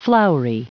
Prononciation du mot floury en anglais (fichier audio)
Prononciation du mot : floury